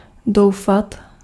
Ääntäminen
Vaihtoehtoiset kirjoitusmuodot (vanhentunut) dreame Synonyymit sweven heaven vision envision lulu Ääntäminen GenAm: IPA : /ˈdɹim/ US : IPA : [dɹim] Tuntematon aksentti: IPA : /ˈdɹiːm/ GenAm: IPA : [d͡ʒɹim]